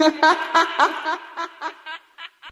Meto Girl Laugh Chant.wav